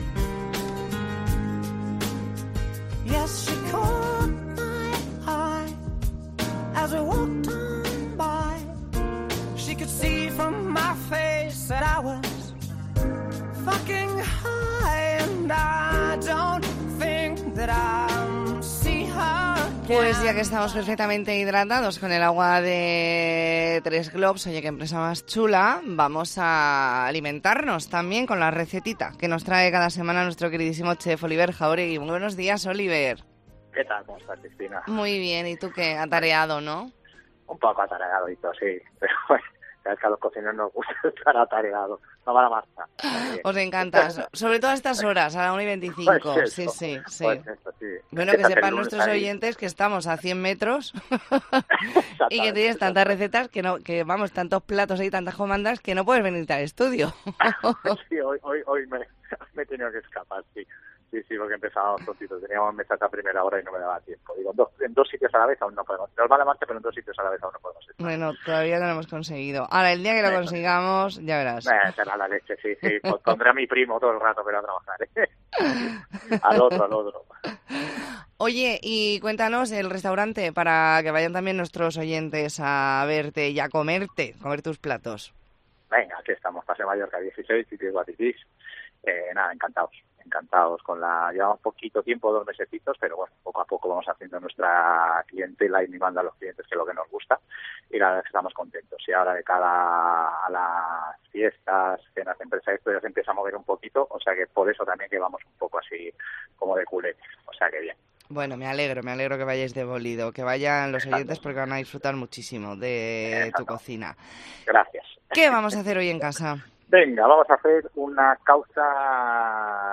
Entrevista en La Mañana en COPE Más Mallorca, lunes 6 de noviembre de 2023.